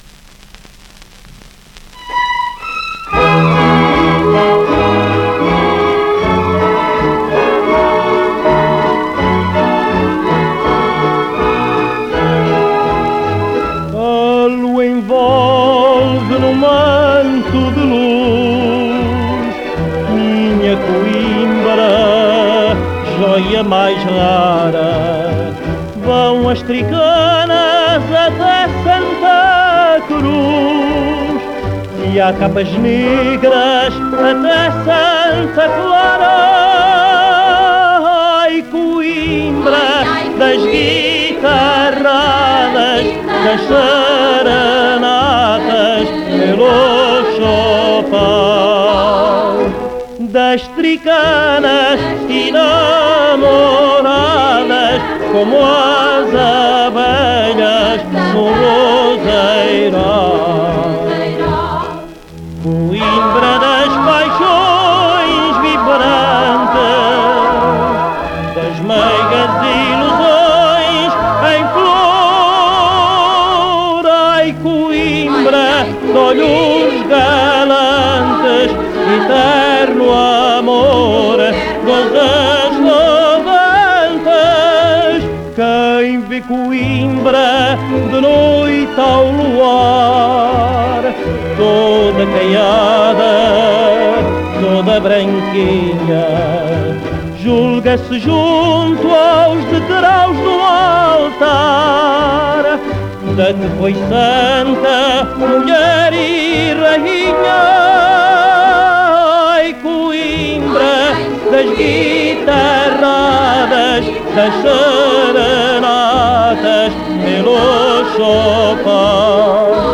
inetmd-fcsh-ifpxx-mntd-audio-coimbra_ao_luar_fado_cancao-958.mp3